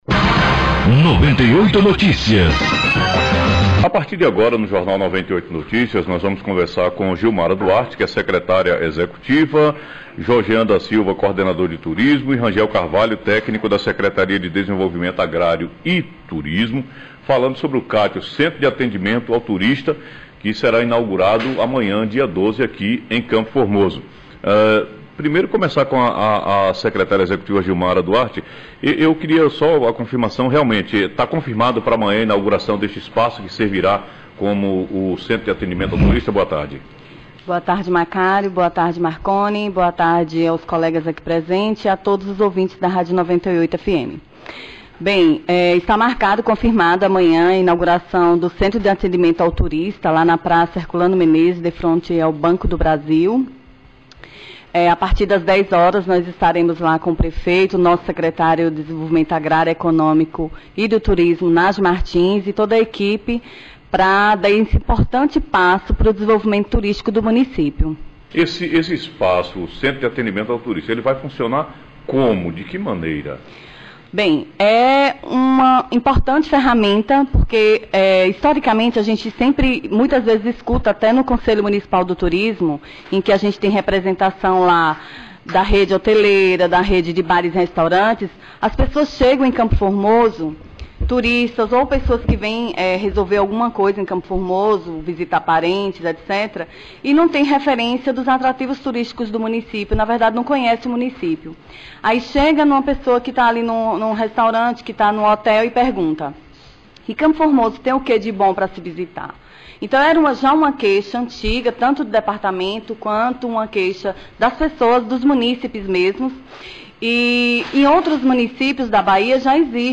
Entrevista: Inauguração do centro de atendimento ao turista